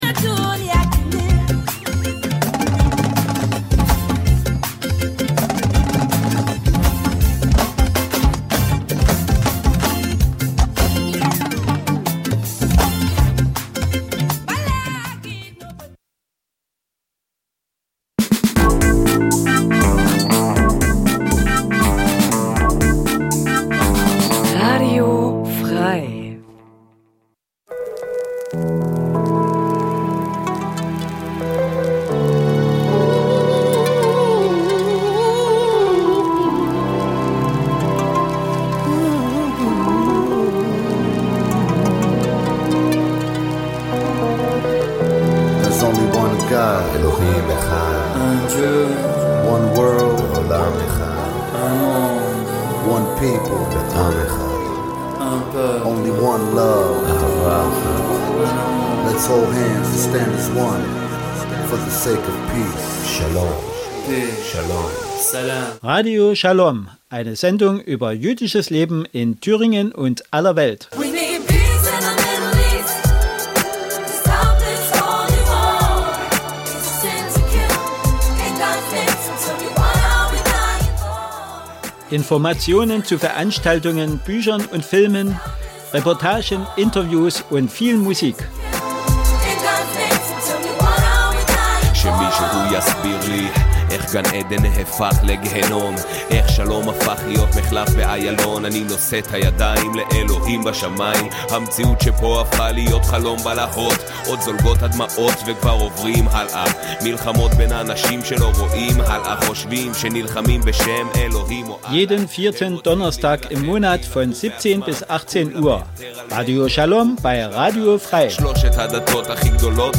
Radio Schalom berichtet �ber j�disches Leben in Deutschland, Diskussionen �ber Tradition und Religion, Juden in Israel und in der Diaspora sowie Musik aus Israel und anderen Teilen der Welt.